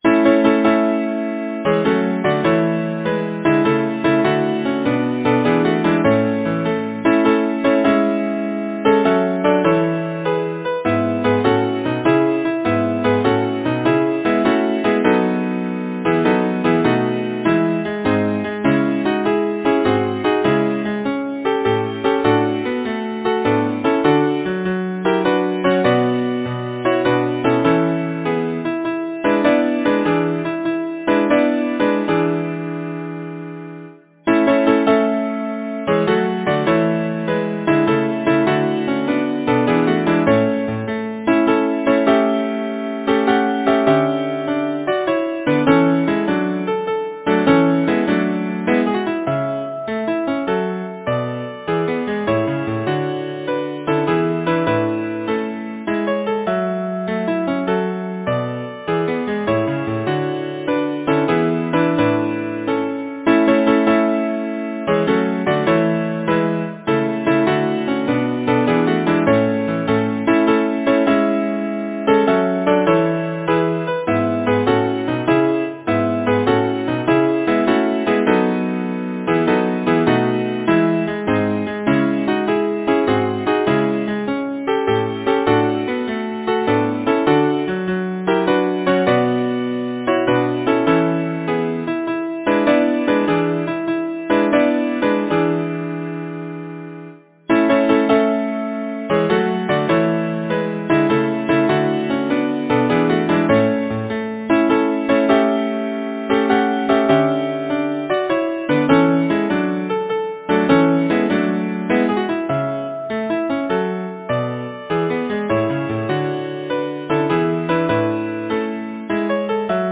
Number of voices: 4vv Voicing: SATB Genre: Secular, Partsong
Language: English Instruments: Piano
A pastiche of Elizabethan madrigal style, from a well-known Elizabethan text.